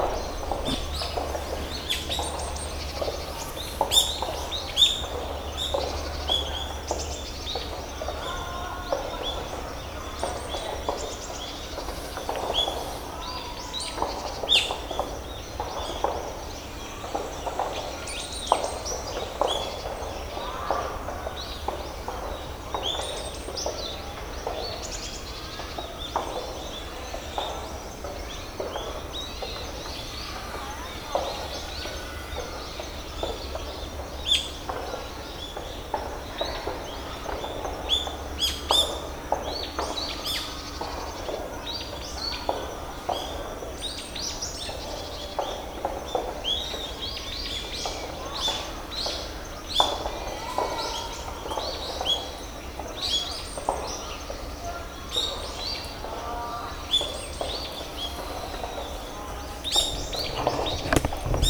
清瀬松山緑地保全地域で野鳥の録音、H2essentialとの録り比べ
清瀬松山緑地保全地域の東側入り口。
入口から少し入った場所。
録音したファイルを、本体でノーマライズしました。
H2essential MS内蔵マイク指向性90°＋
ZOOM　ヘアリーウィンドスクリーン WSH-2e